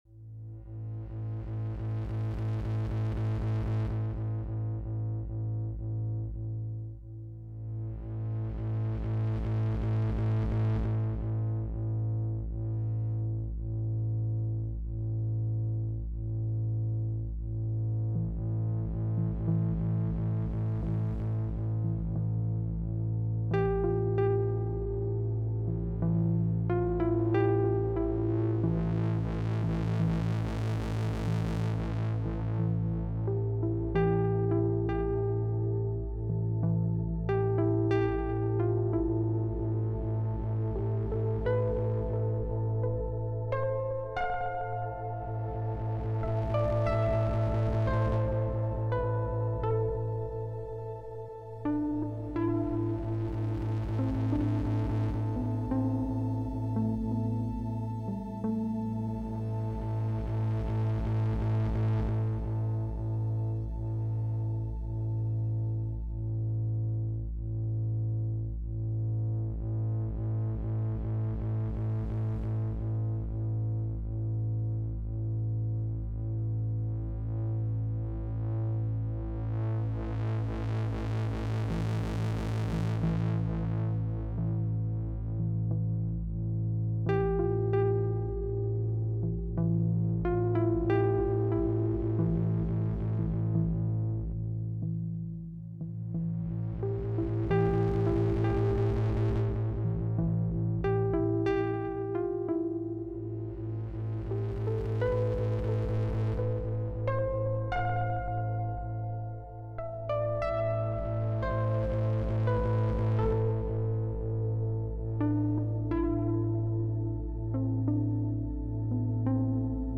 So, ich hab hier mal drei Spuren Preset Sounds zusammen musikalisch in Einklang gebracht und die internen Effekte ausgeschaltet. Direkt in die DAW, keine weiteren Effekte. Modulation aus der Sytnese Engine auf die Voices ist natürlich noch voll da. Und ich hab ein bissi am Morphee gespielt.